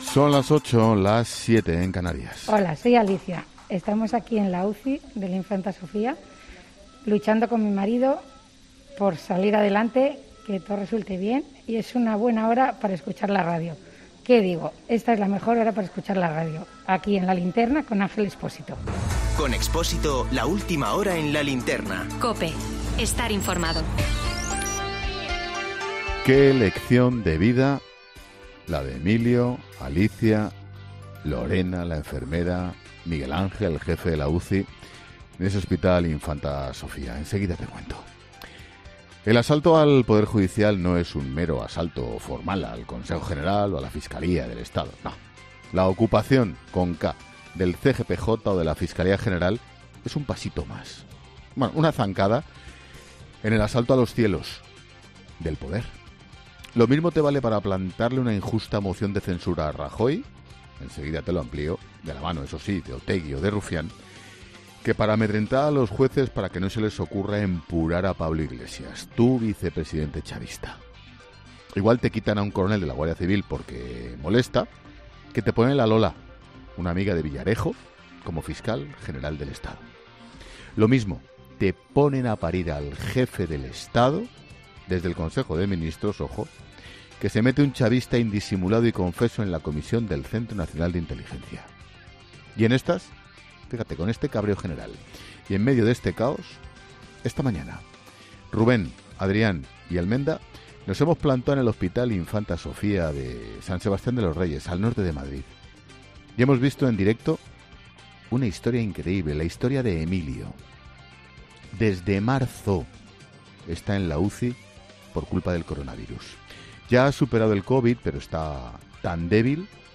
AUDIO: El presentador de 'La Linterna' analiza las últimas polémicas del Gobierno en medio de la pandemia